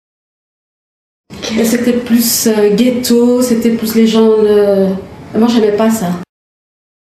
uitspraak Ghetto